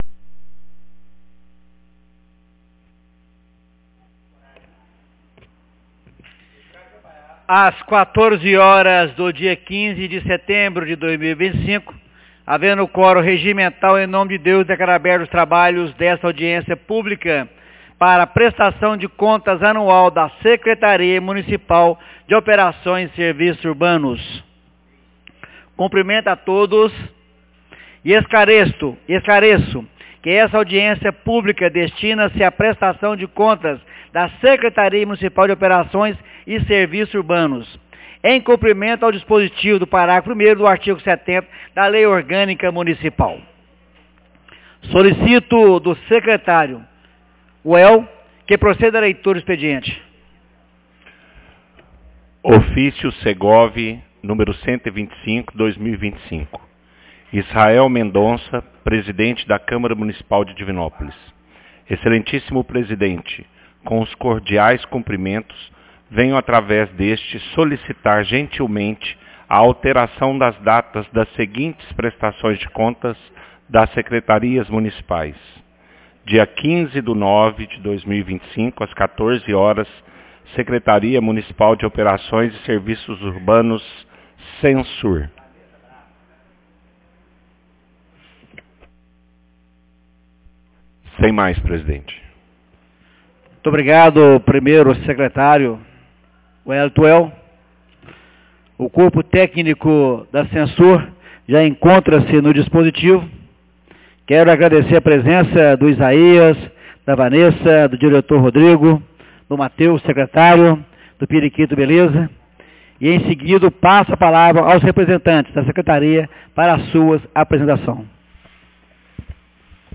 Audiencia publica Prestação de contas da sec mun de Operações e Serviços Urbanos 15 de setembro de 2025